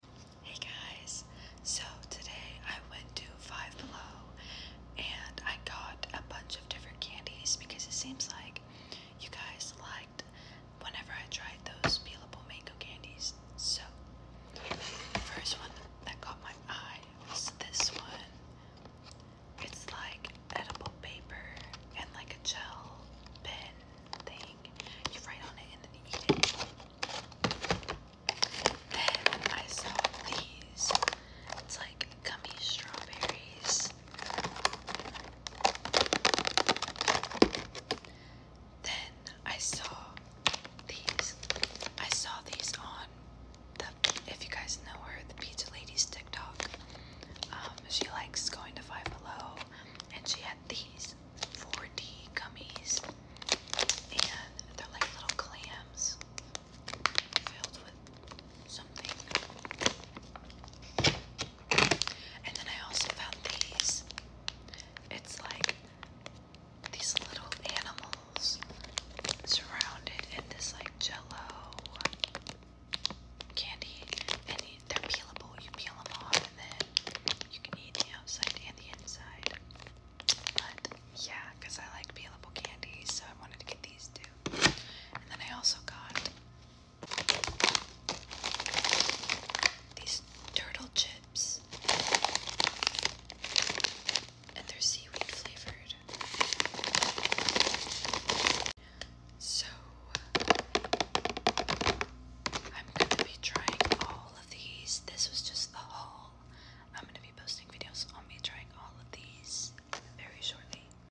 asmr Five Below candy haul